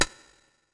PixelPerfectionCE/assets/minecraft/sounds/item/shovel/flatten3.ogg at mc116
flatten3.ogg